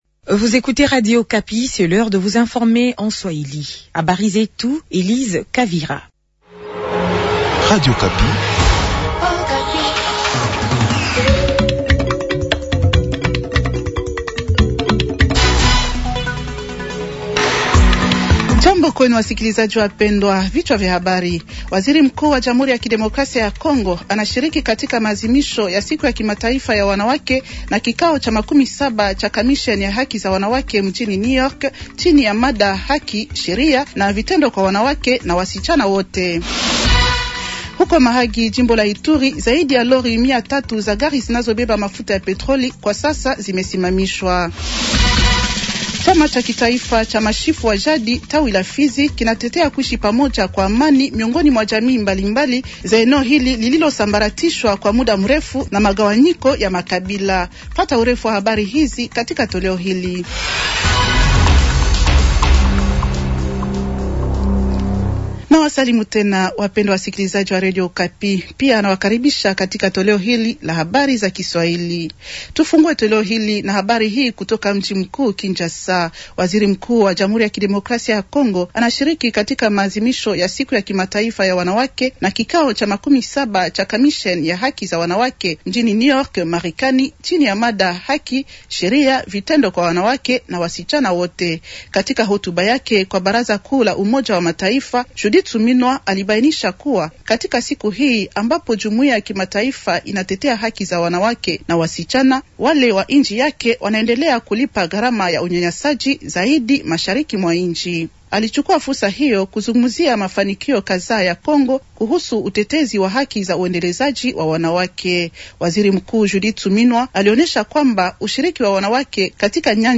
Journal swahili de mercredi matin 110326